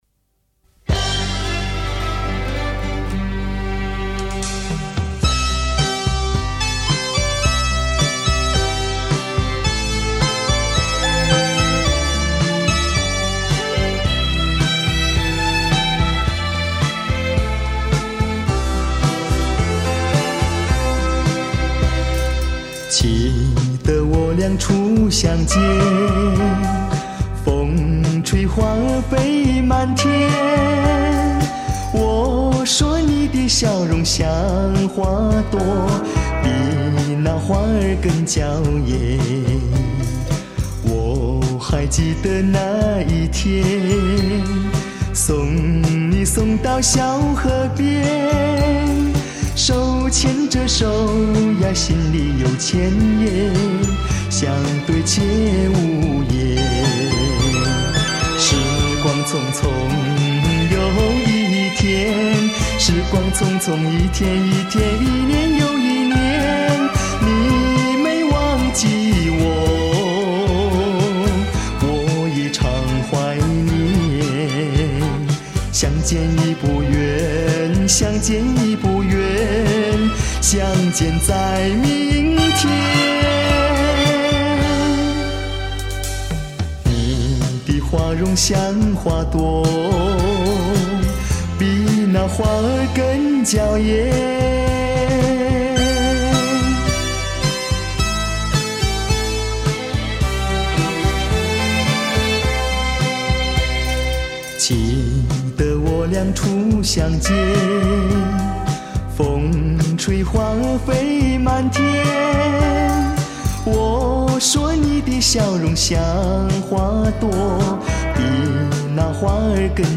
这一集是女歌男唱